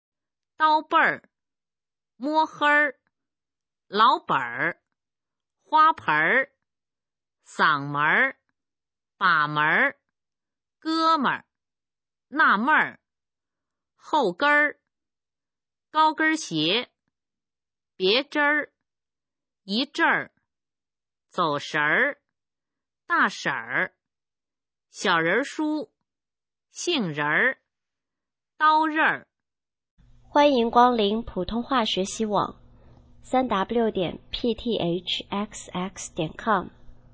普通话水平测试用儿化词语表示范读音第8部分